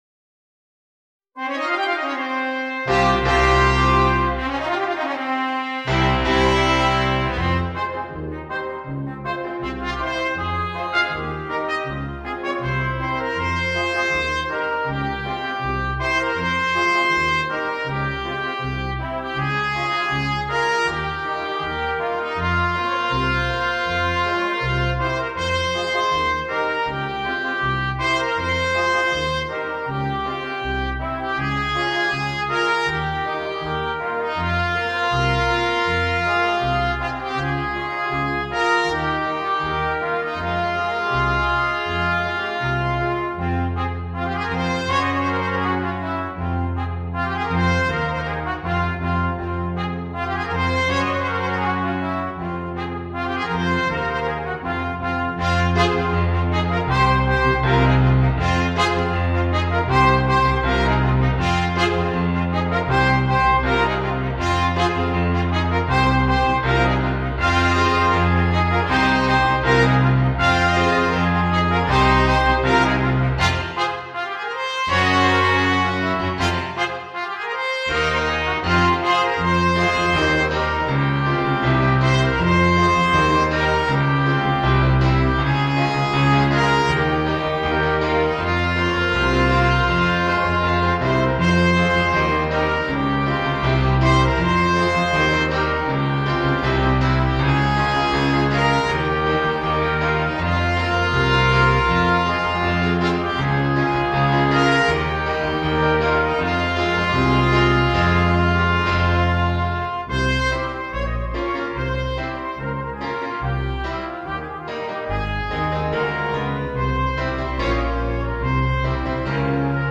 Gattung: Brass Quartet
Besetzung: Ensemblemusik für 4 Blechbläser